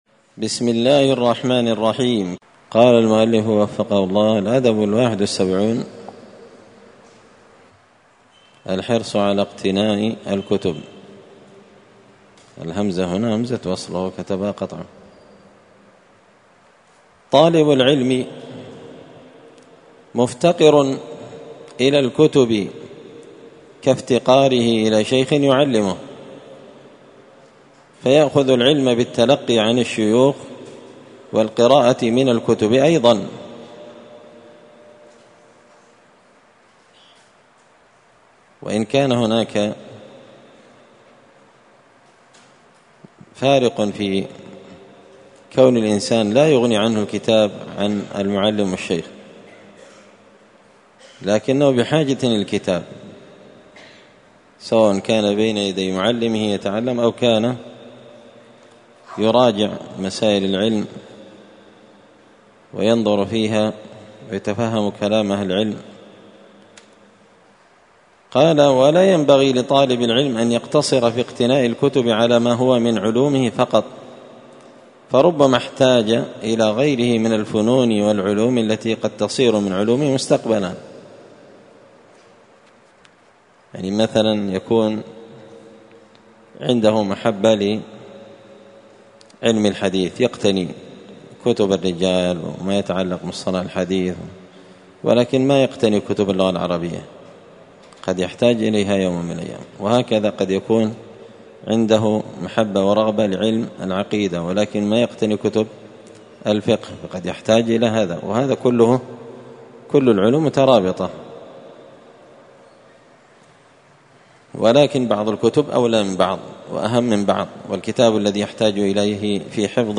تعليق وتدريس الشيخ الفاضل:
الأثنين 6 محرم 1445 هــــ | الدروس، النبذ في آداب طالب العلم، دروس الآداب | شارك بتعليقك | 12 المشاهدات